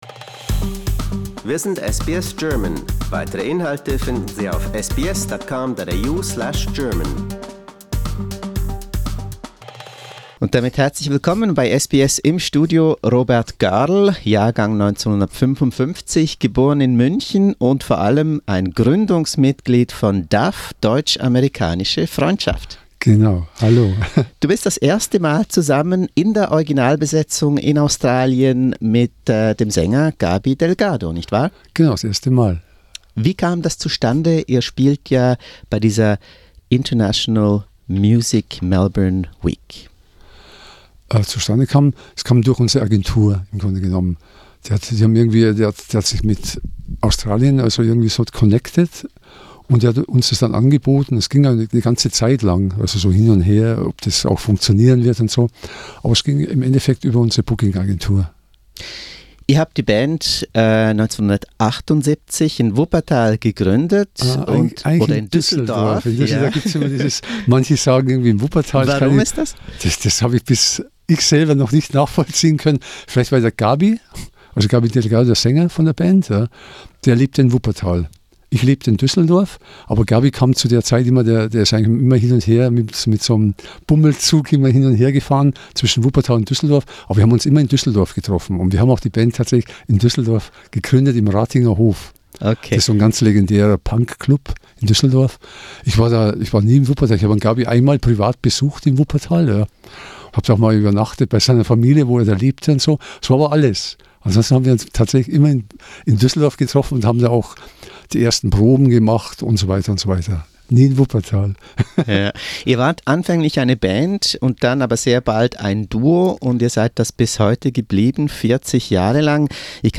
Today: A fascinating interview with DAF (Deutsch Amerikanische Freundschaft) who visited us prior to their first ever (!) concert in Australia.